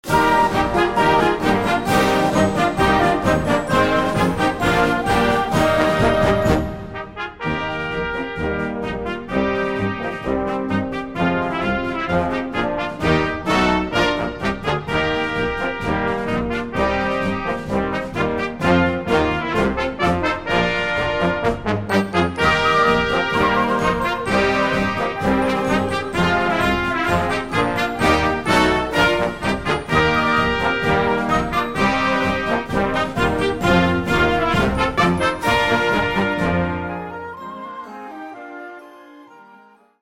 Marszowa Znacznik